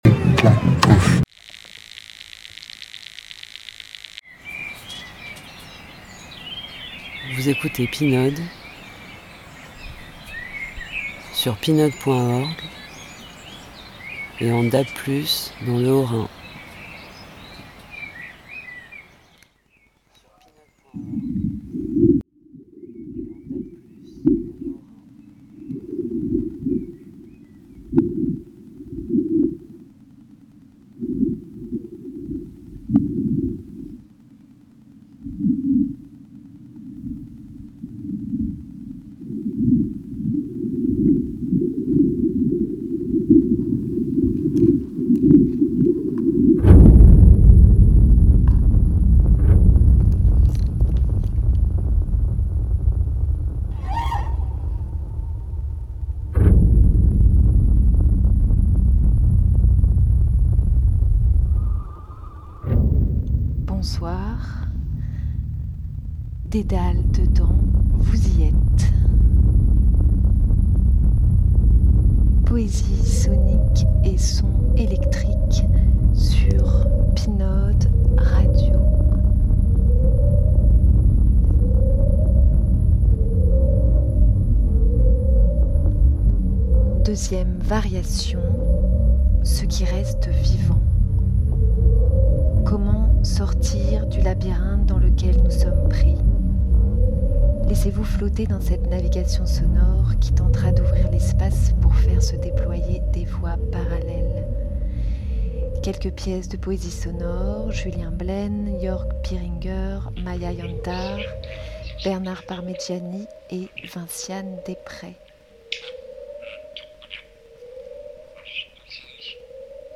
Poésie sonique et sons électriques
minuit Pour sortir du labyrinthe dans lequel nous sommes pris, Dédales Dedans vous emmène dans une navigation sonore qui tentera d’ouvrir l’espace, celui de nos intérieurs, celui de nos esprits, pour qu’ils trouvent des voies parallèles où se déployer ….